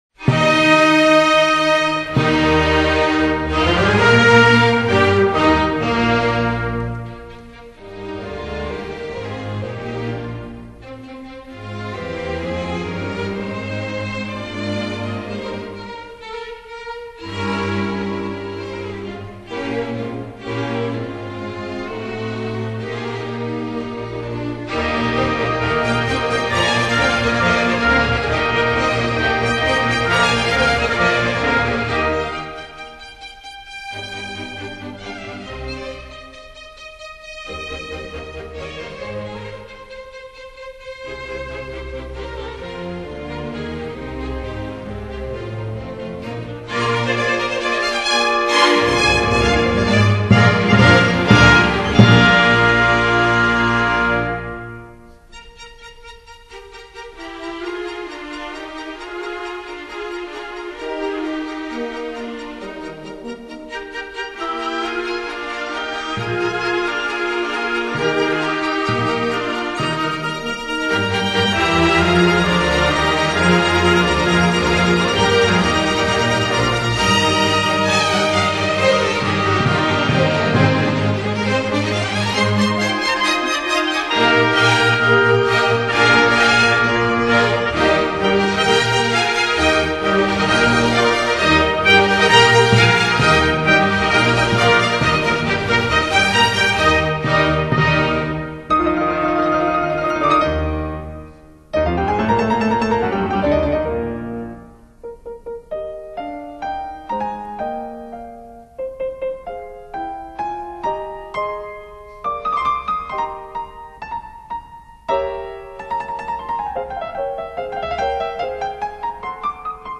双钢琴协奏曲第1乐章-快板K.365(降E大调)